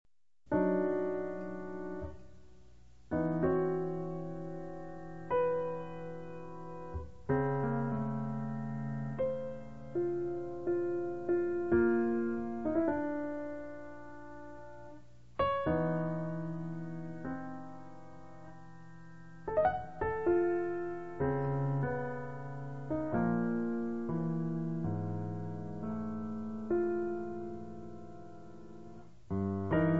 • jazz
• registrazione sonora di musica